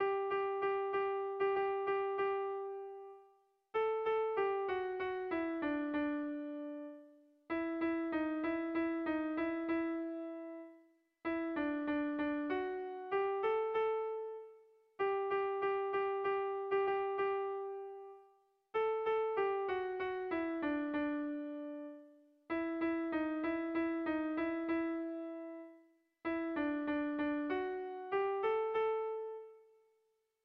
Kontakizunezkoa
Zortziko berdina, 4 puntuz eta 8 silabaz (hg) / Lau puntuko berdina, 16 silabaz (ip)
ABAB